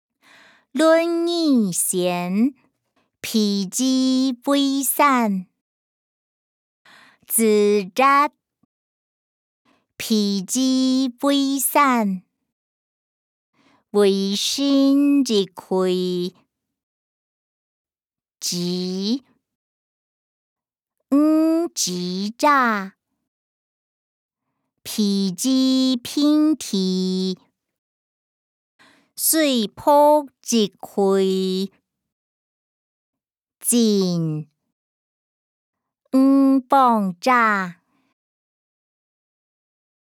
經學、論孟-論語選．譬如為山音檔(海陸腔)